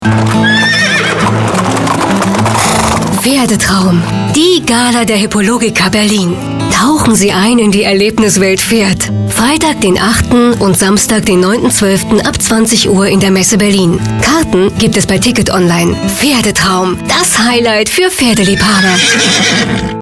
deutsche Sprecherin für Hörbuch, Hörspiel, Werbung, Dokumentarfilm, Voice over, Image- und Industriefilm, POS, Multimedia
Sprechprobe: Werbung (Muttersprache):
german female voice over artist.